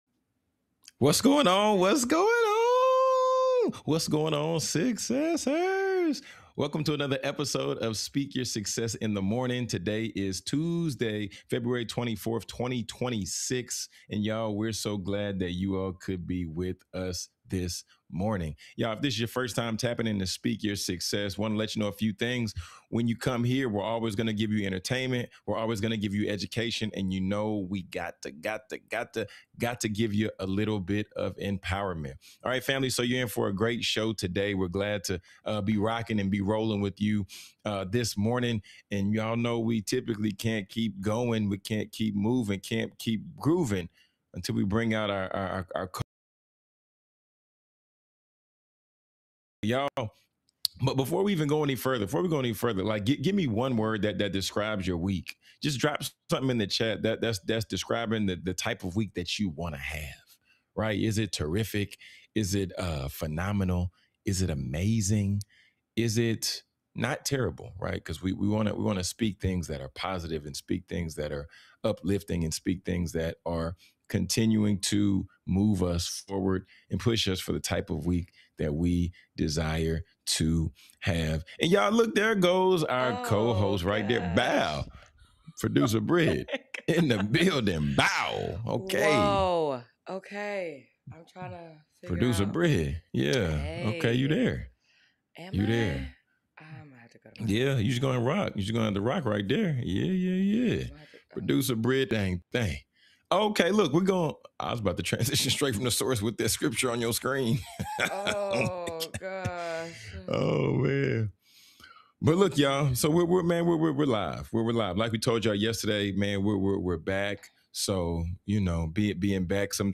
Switch it up on Teach Me Tuesday where we're bringing you game-changing convos with industry leaders who've been there, done that.